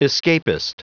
Prononciation du mot escapist en anglais (fichier audio)